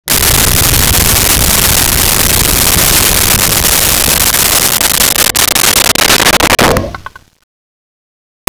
Creature Snarl 03
Creature Snarl 03.wav